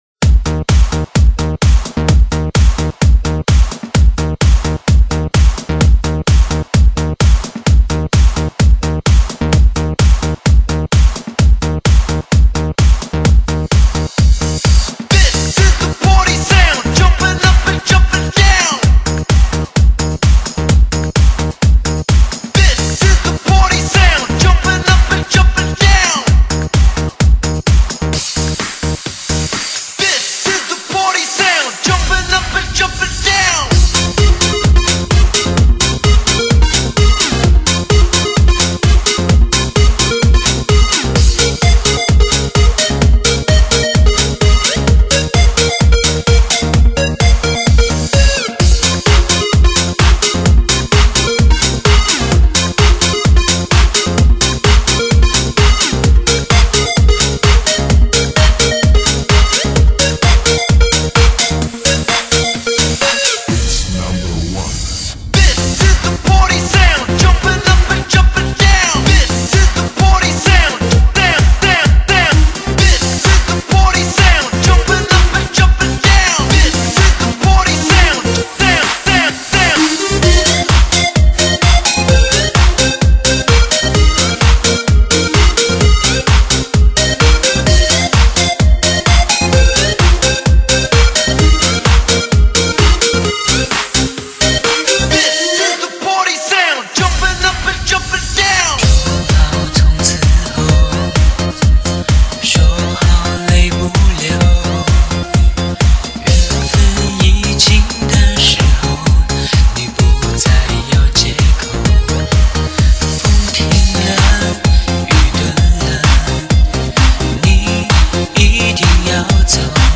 经专业DJ之手将多种音乐元素完美融和，节奏、旋律及情绪等完全不同的音乐被接连得天衣无缝，点燃你的激情。
火热电子舞曲与顶级音乐录音技术的完美结合。
根据汽车空间构造录制，车内也能开PARTY